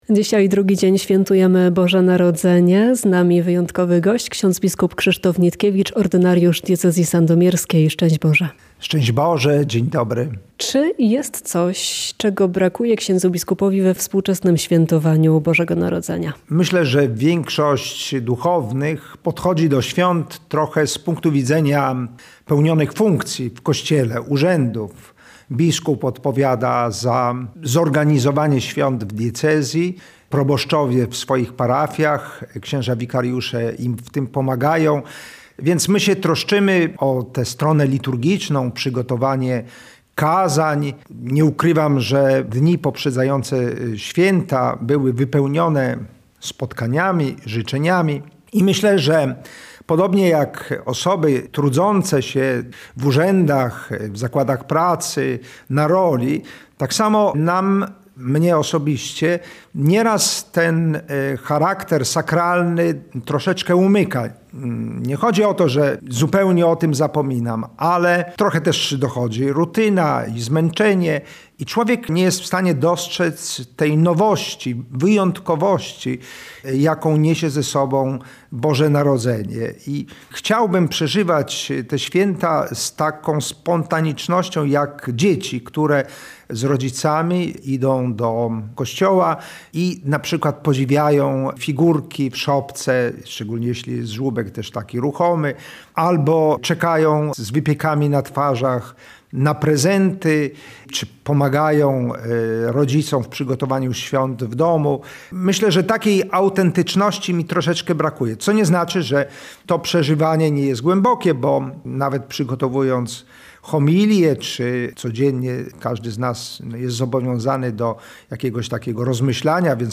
– Ewangelia zawsze była w świecie znakiem sprzeciwu – podkreśla ks. bp Krzysztof Nitkiewicz, ordynariusz diecezji sandomierskiej, który dziś (26 grudnia) o poranku był gościem programu „Świąteczne spotkania” w Radiu Kielce.